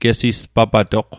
Pronunciation Guide: ge·sis·pa·ba·dohk